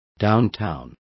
Complete with pronunciation of the translation of downtowns.